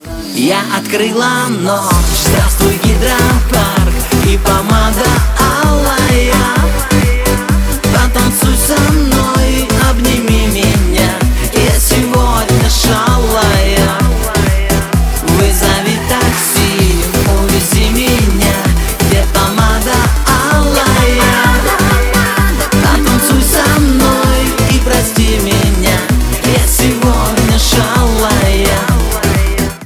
• Качество: 128, Stereo
поп
веселые
русская попса